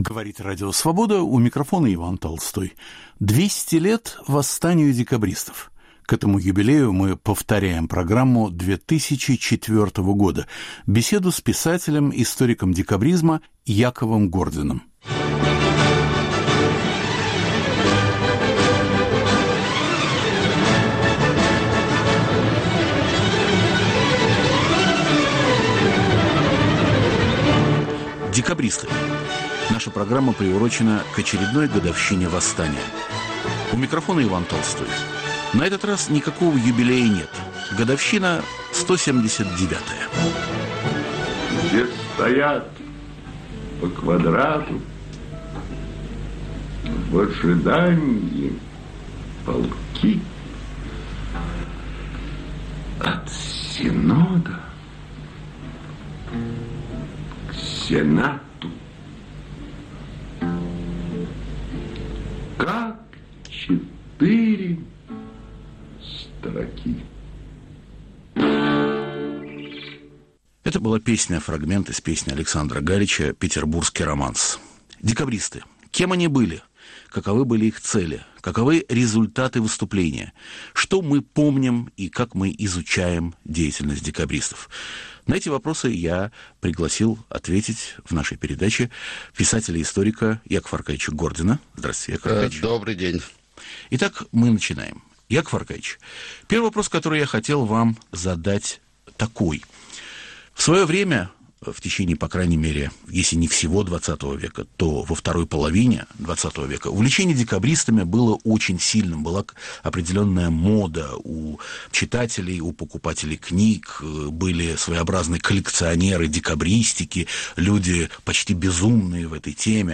Мы повторяем программу 2004 года – беседу с историком декабризма Яковом Гординым